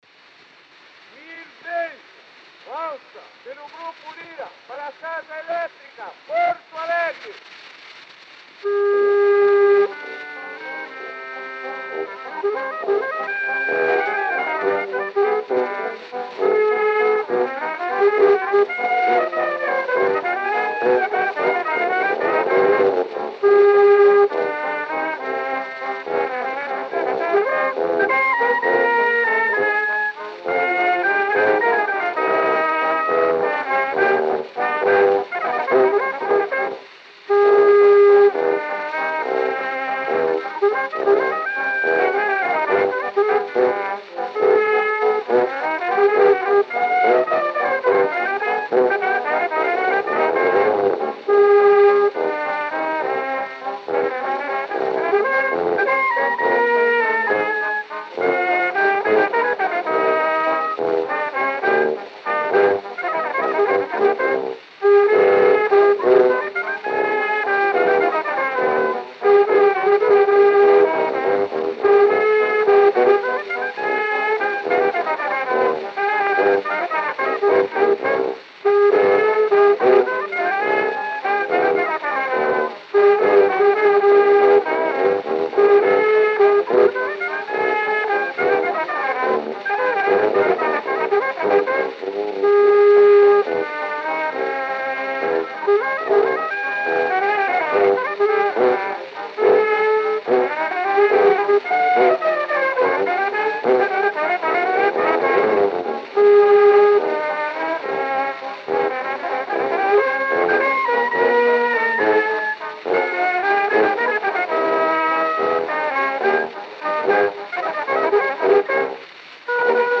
O gênero musical foi descrito como "valsa".